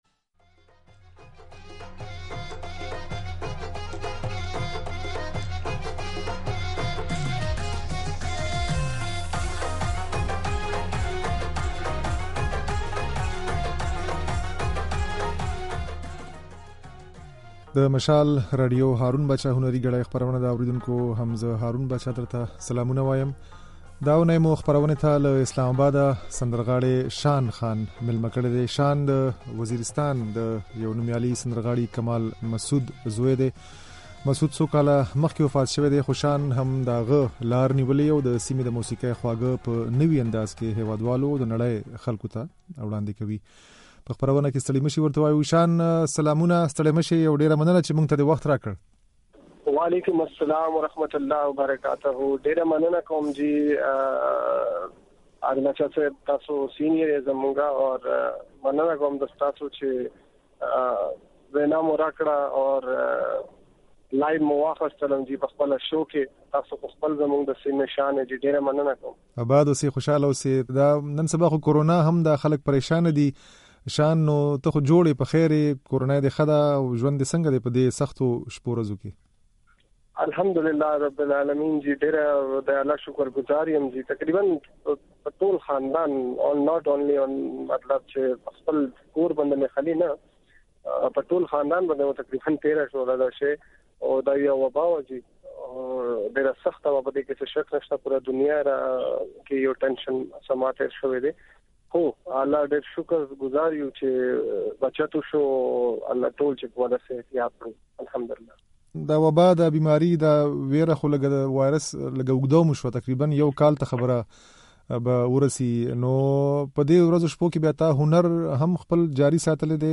د نوموړي دا خبرې او ځينې سندرې يې د غږ په ځای کې اورېدای شئ.